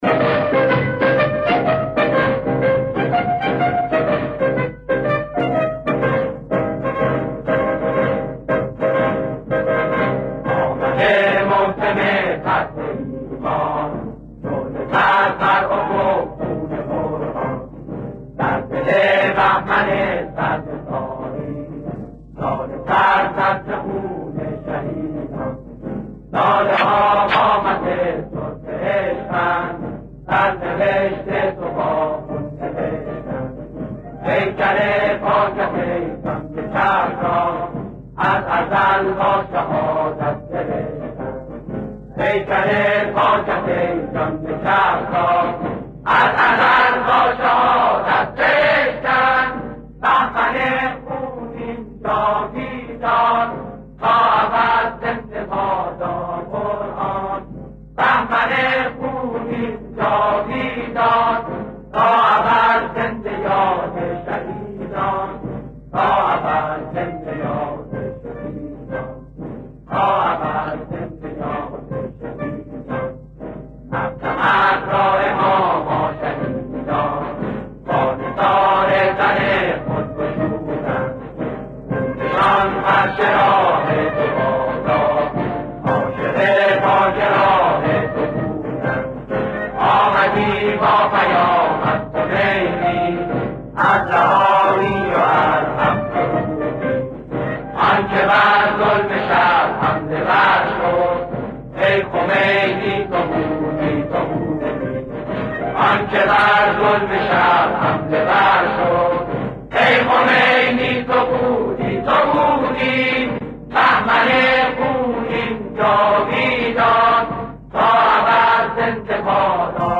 اجرای گروهی: سرود